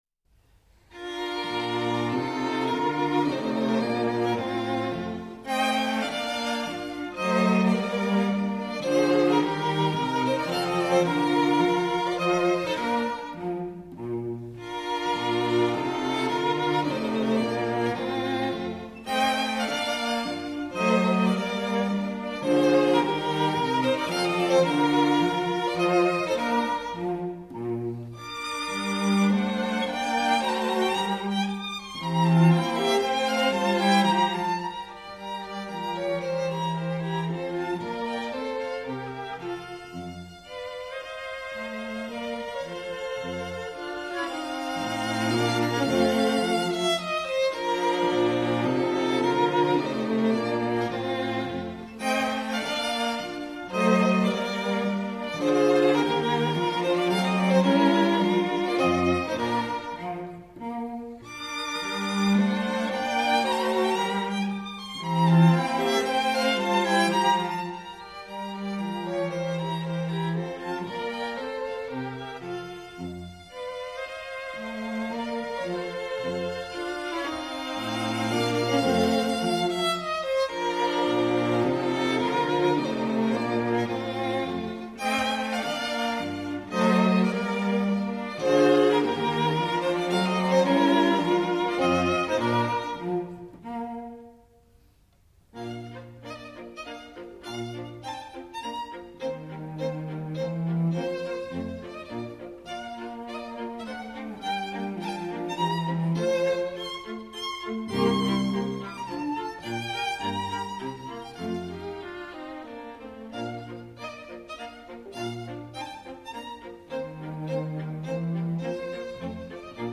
String Quartet in B flat major "The Hunt"
Menuetto moderato